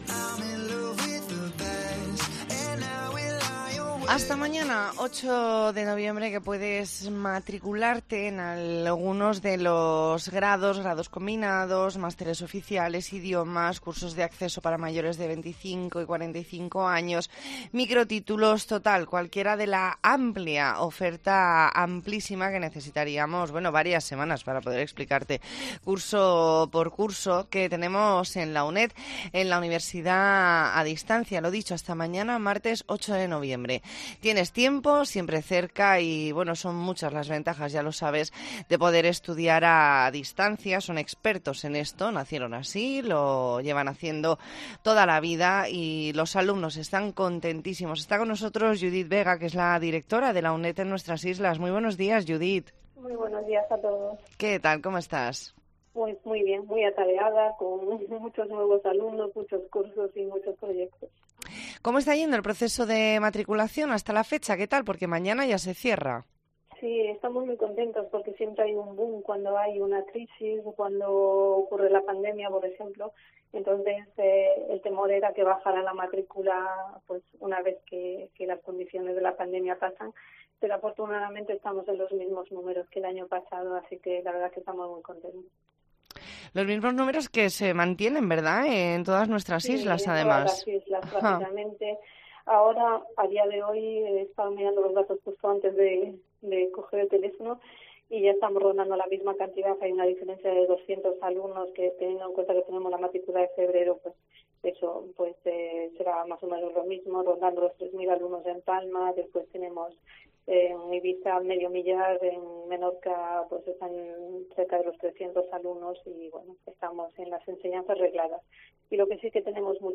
ntrevista en La Mañana en COPE Más Mallorca, lunes 7 de noviembre de 2022.